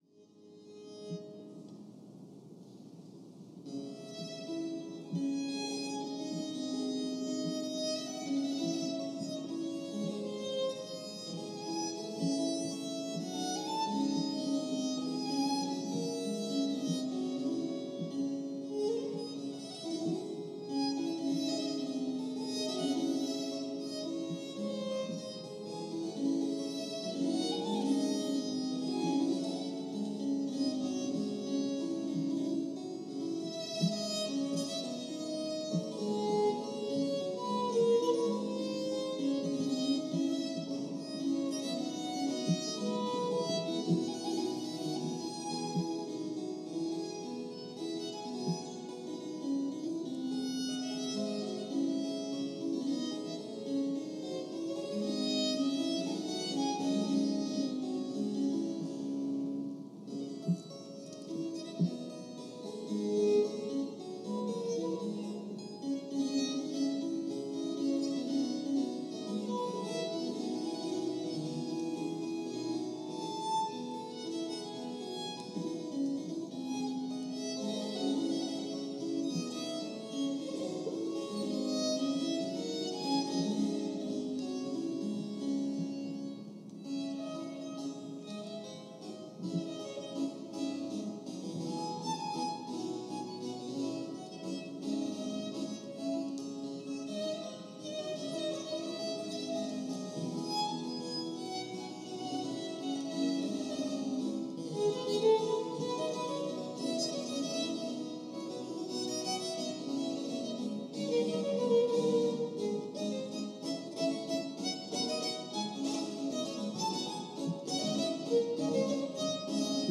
Live (lo-fi recording) at the British Museum